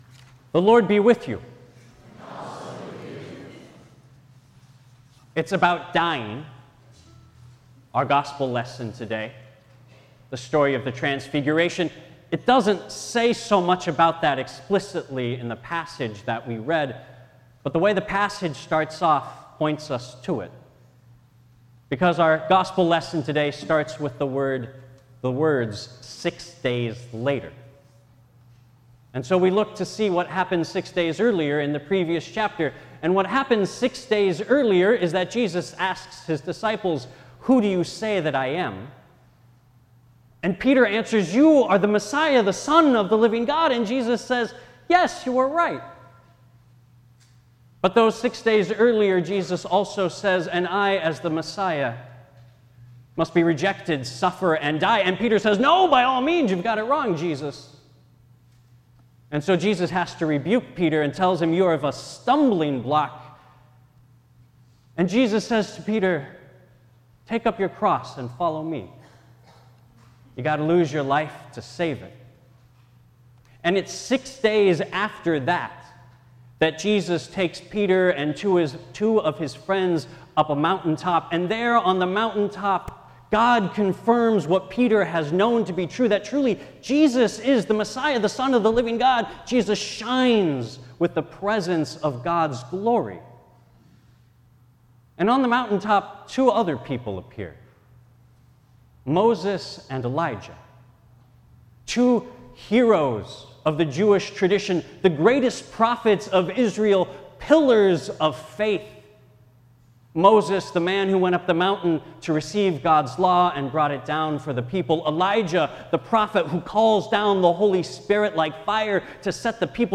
Faith Lutheran Church Sermons